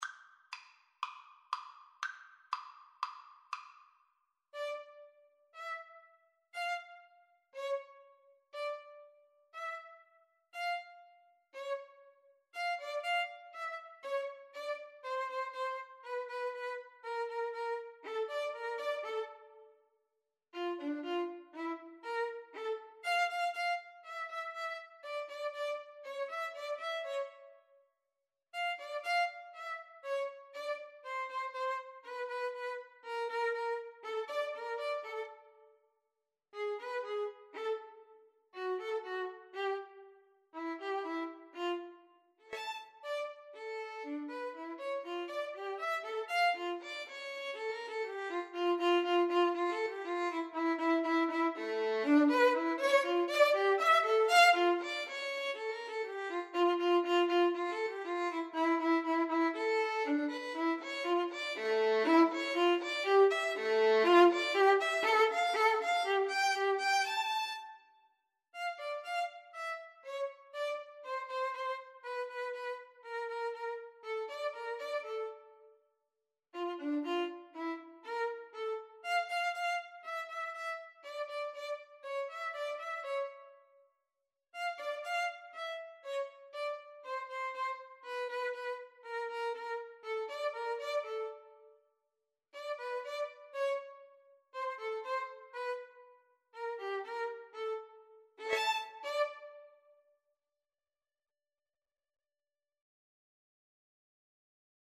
Free Sheet music for Clarinet-Violin Duet
4/4 (View more 4/4 Music)
D minor (Sounding Pitch) E minor (Clarinet in Bb) (View more D minor Music for Clarinet-Violin Duet )
Fairly slow and graceful = 120
Clarinet-Violin Duet  (View more Intermediate Clarinet-Violin Duet Music)
Classical (View more Classical Clarinet-Violin Duet Music)